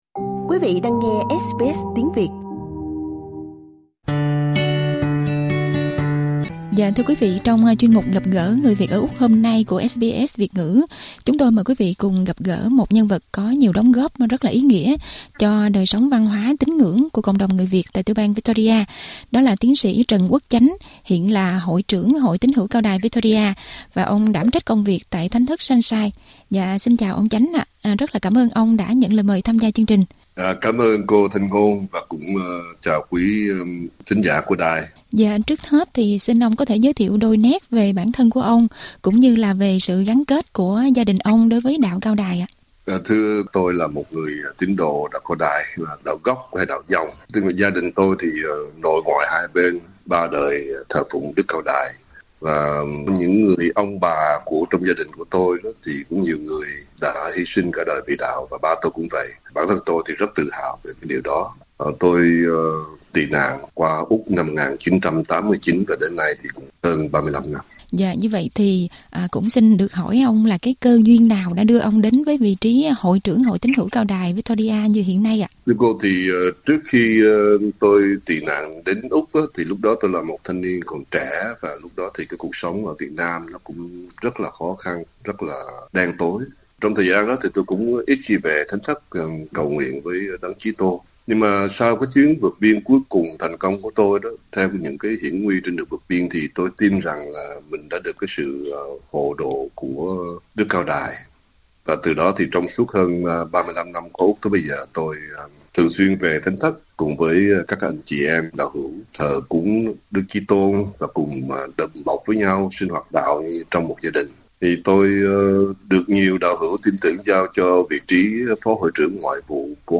Trong cuộc trò chuyện với SBS Việt ngữ